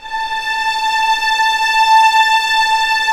Index of /90_sSampleCDs/Roland LCDP13 String Sections/STR_Violins IV/STR_Vls7 p wh%